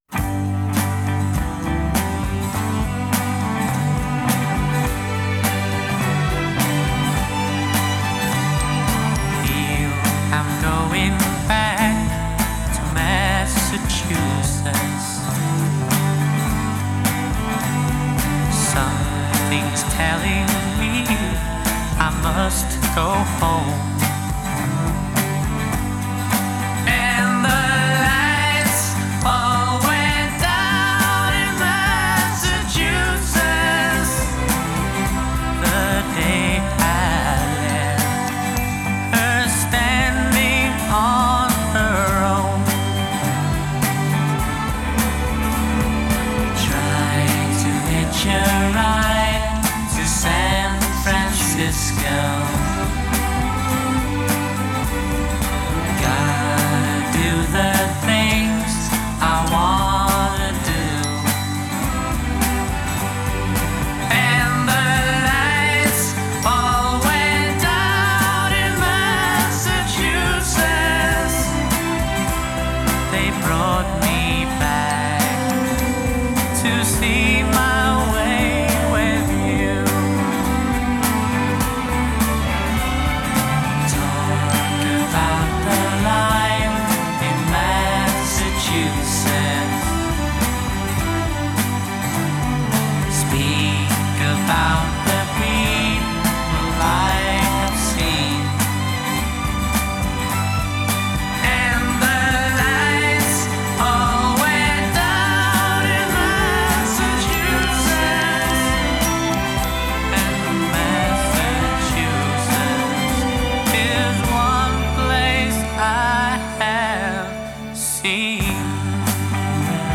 Genre: Pop, Pop Rock, Disco